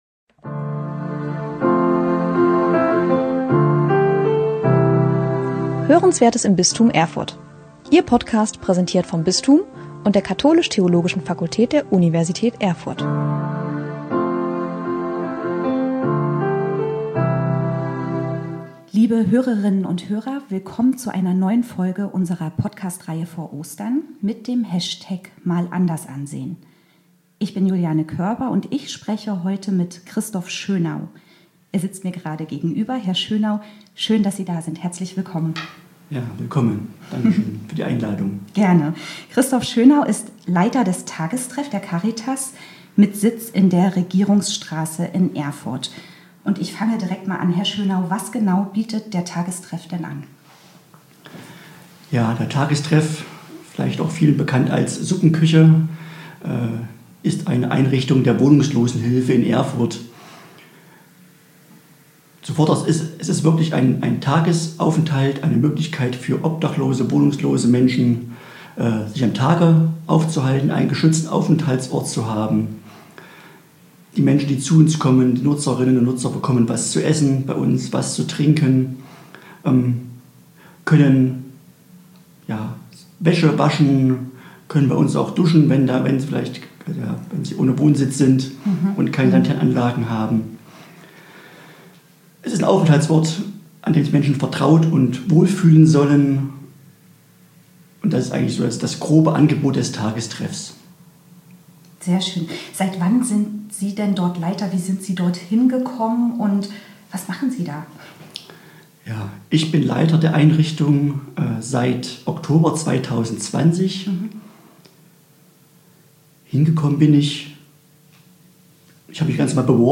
Spannende Vorträge und Interviews aus dem Bistum Erfurt, aufgenommen bei Veranstaltungen des Katholischen Forums, der katholisch-theologischen Fakultät Erfurt sowie Fortbildungen im Bistum Erfurt.